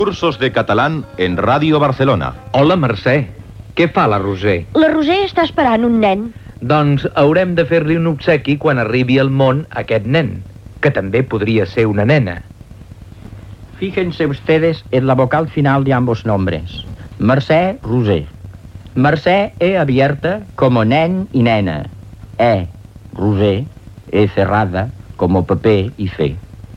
Indicatiu del programa, fragment d'un diàleg per exemplificar la dicció de les lletres e oberta i e tancada i explicació.
Extret de Crònica Sentimental de Ràdio Barcelona emesa el dia 29 d'octubre de 1994.